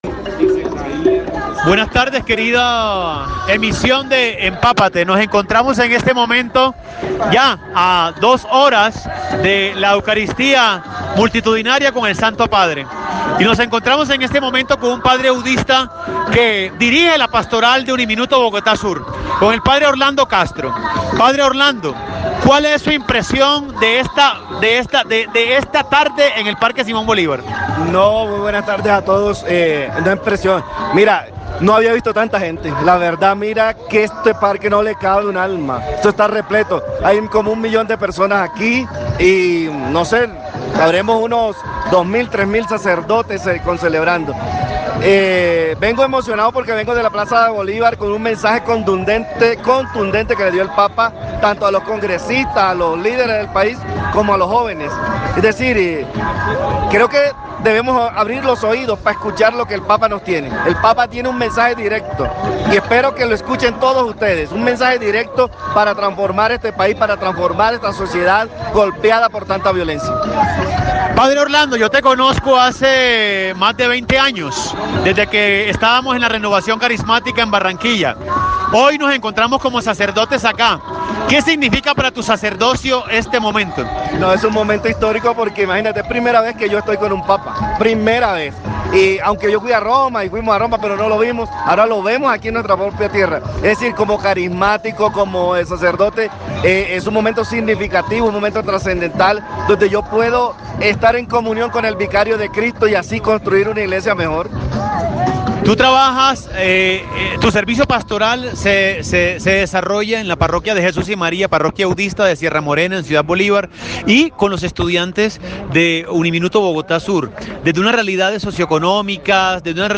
En diálogo con UNIMINUTO Radio